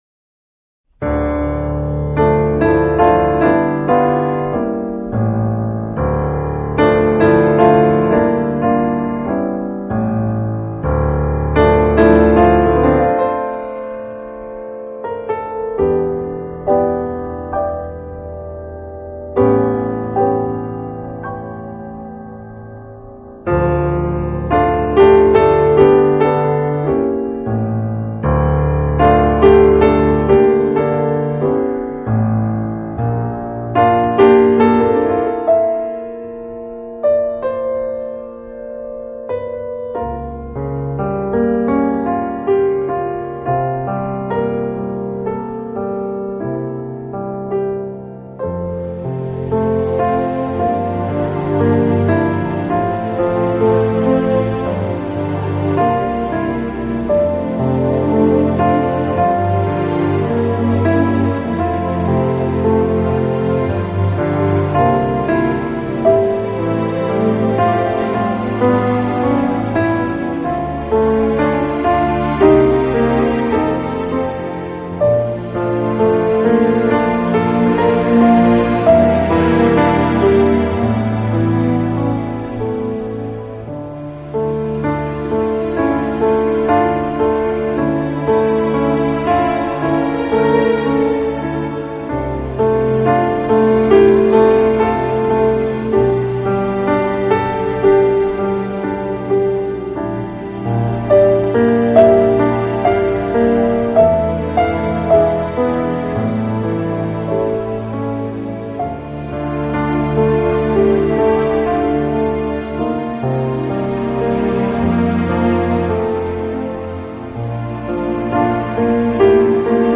【钢琴专辑】
为原作，CD2多为流行金曲的重新演绎。